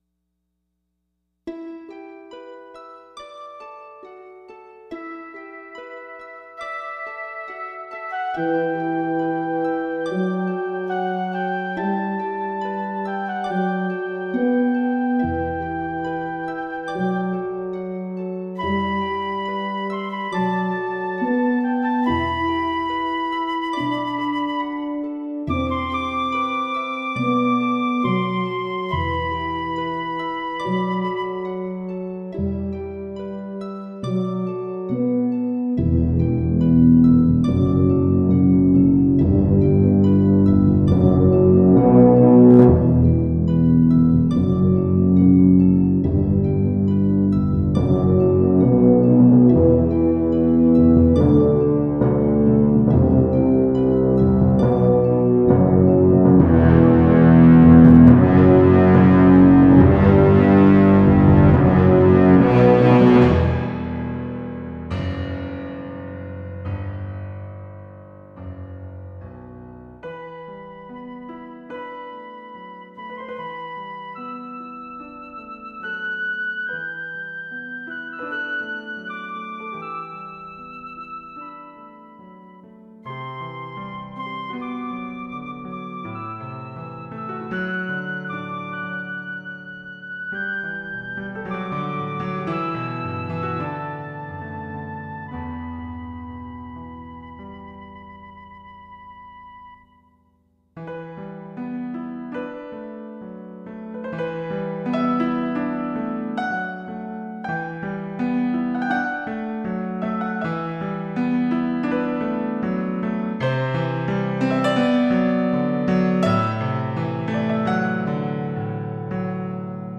Thematic, instrumental/orchestral piece(Part 3 of 3)